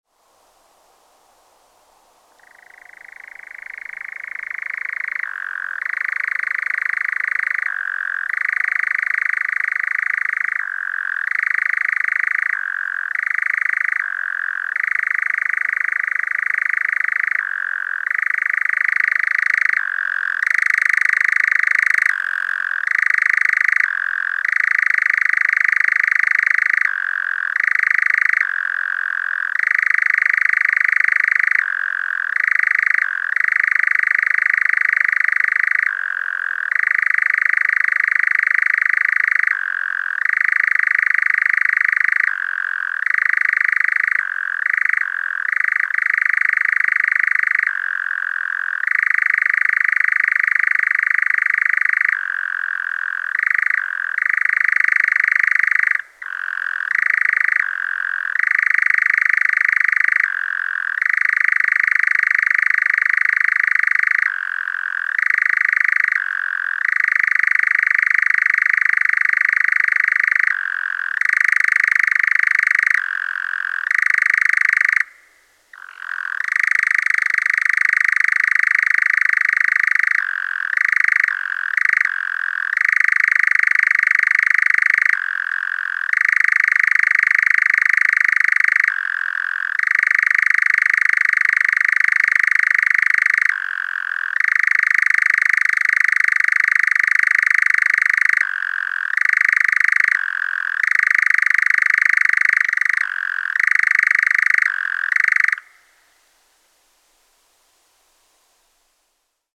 Succiacapra
(Caprimulgus europaeus)
Succiacapre-Caprimulgus-europaeus-europaeus.mp3